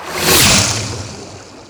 WaterWhoosh.wav